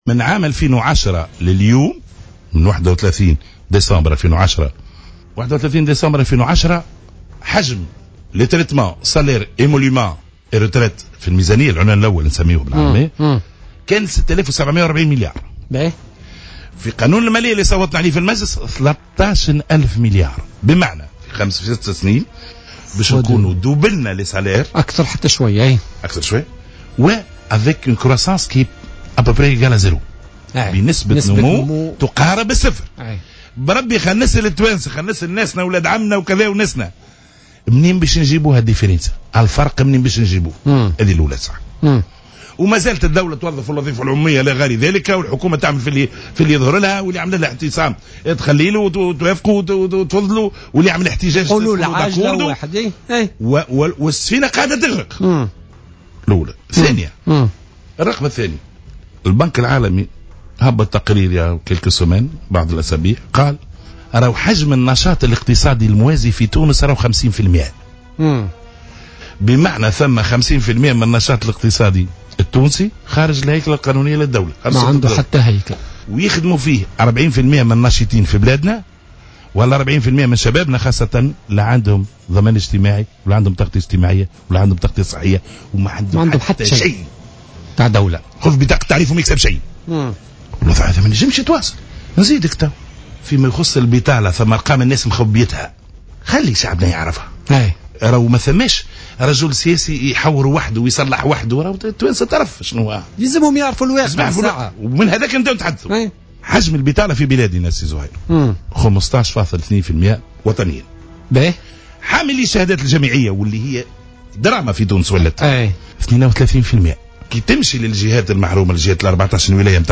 وأضاف بلحاج علي، ضيف برنامج "بوليتيكا" اليوم أن حجم الأجور تضاعف في تونس خلال ال5 سنوات الأخيرة، في ظل إصرار الحكومات المتعاقبة على التشغيل في الوظيفة العمومية والاستجابة لمطالب المعتصمين ، مقابل نسبة نمو تقارب الصفر، وفق تعبيره.